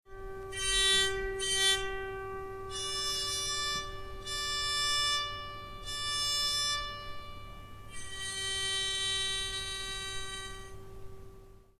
kl.Streichpsalter.mp3